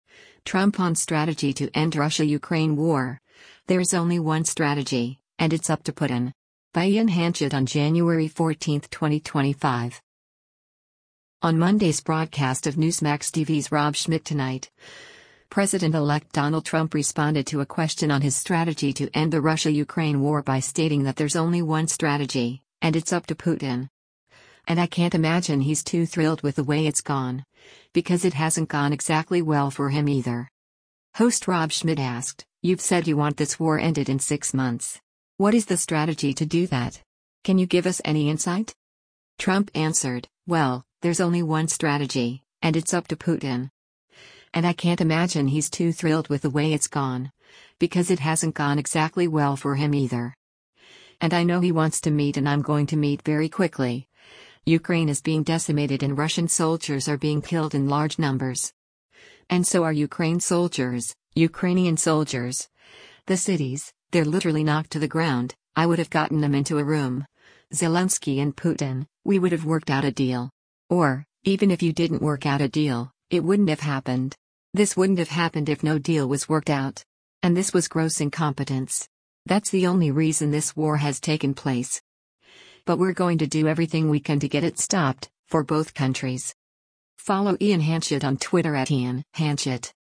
Host Rob Schmitt asked, “You’ve said you want this war ended in six months. What is the strategy to do that? Can you give us any insight?”